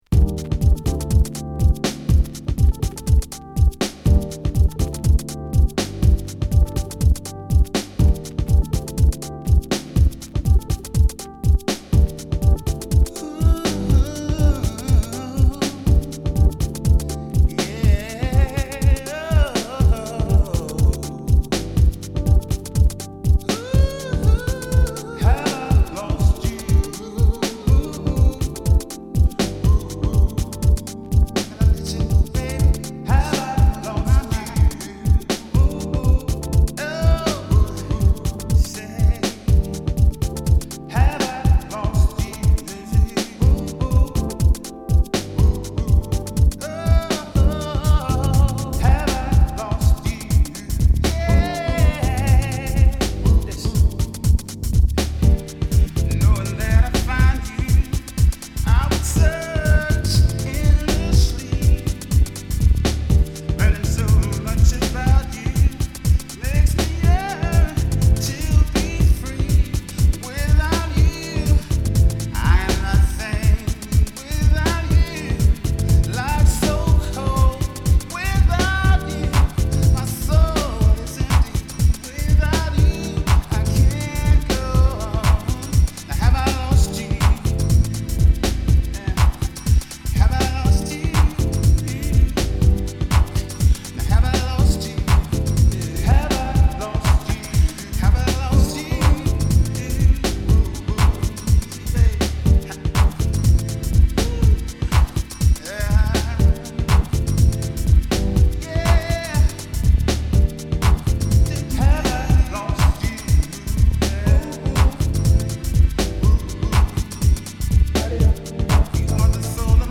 Extended Vocal Mix
Urban Instrumental